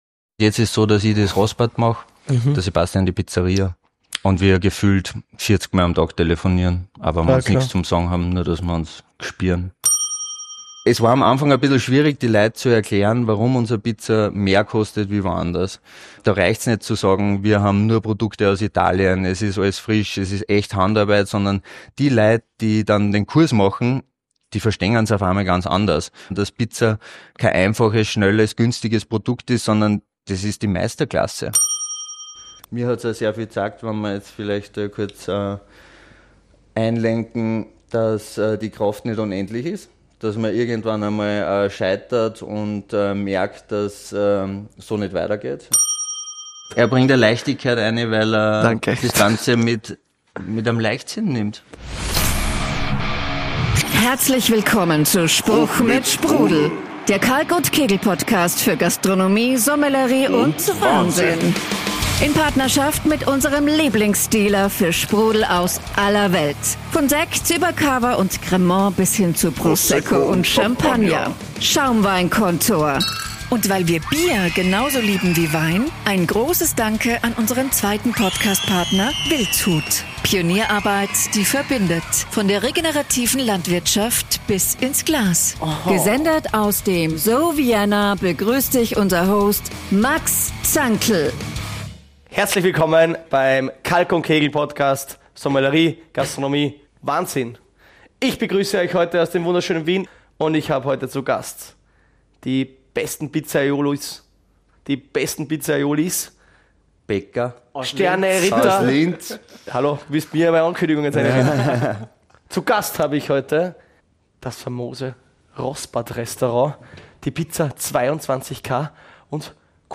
Ein Talk über Höhen, aber auch über Tiefen.